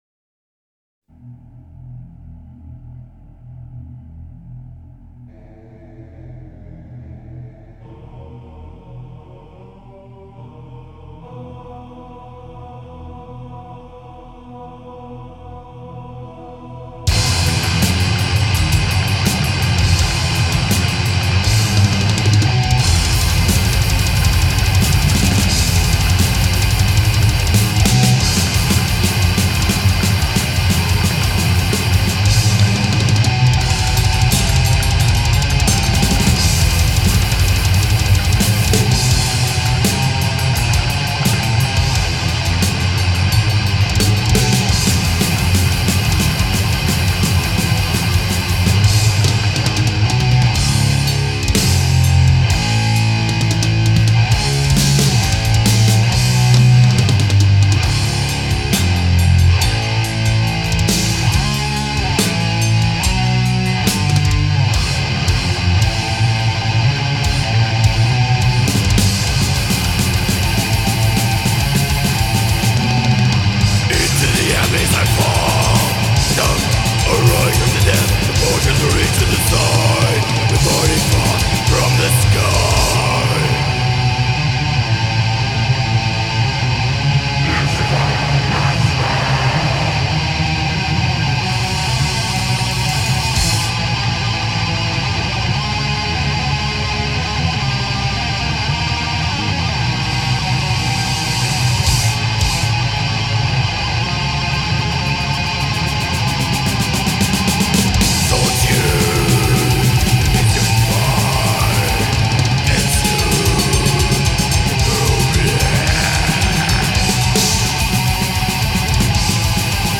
Death Metal